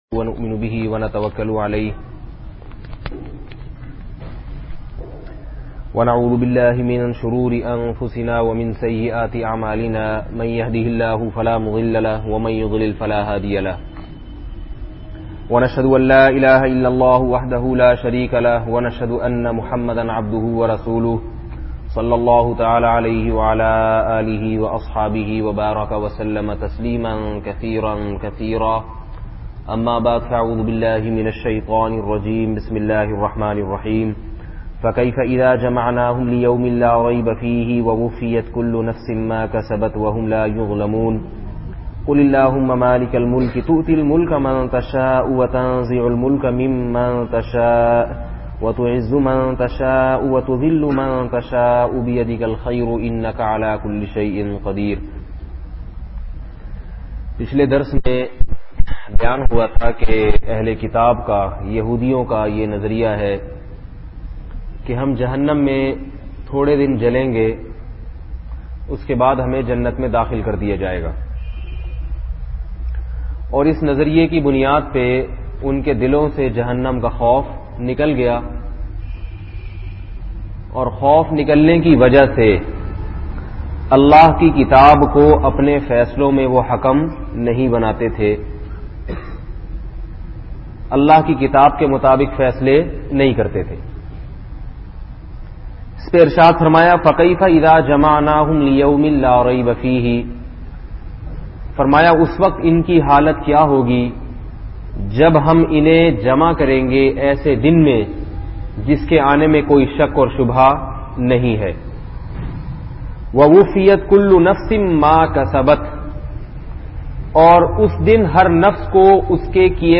Ezat aur zilat Serif Allah kay Haat may bayan mp3